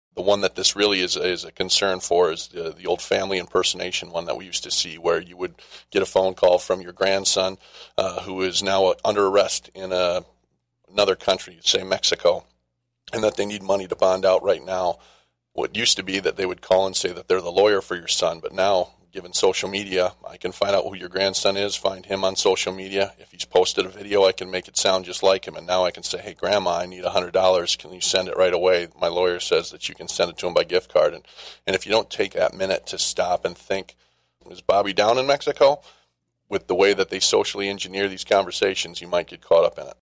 Sheriff Eric DeBoer explained one familiar tactic.